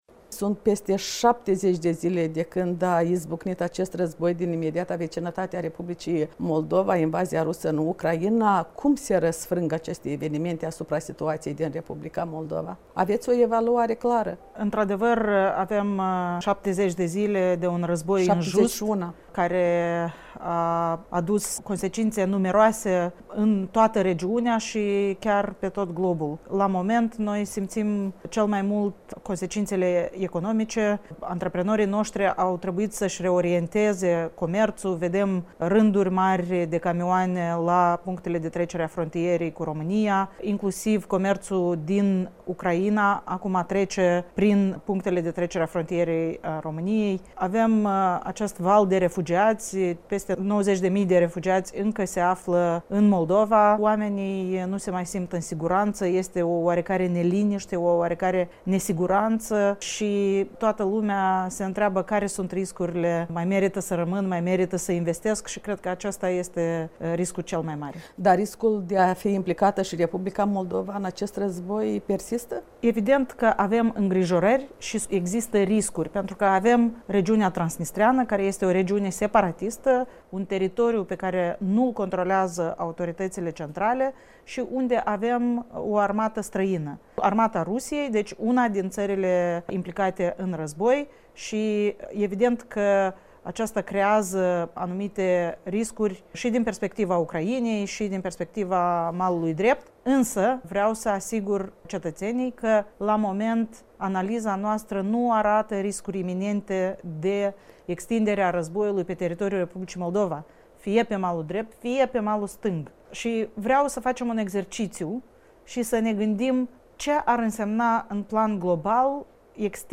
Prima parte a interviului cu premierul Natalia Gavrilița